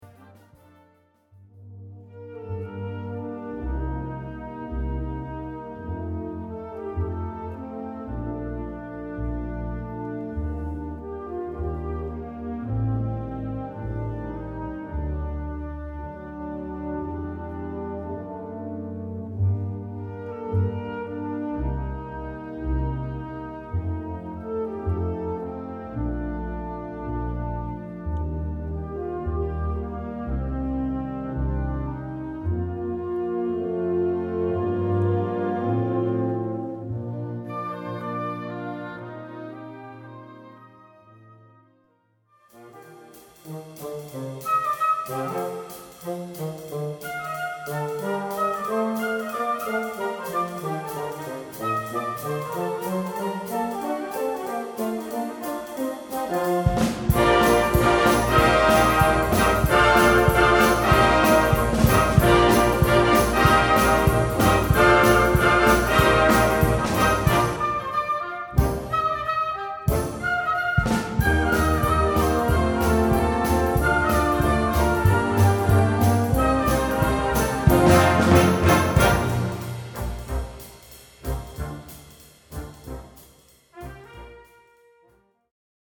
ENSEMBLE INSTRUMENTAL
Cours complémentaire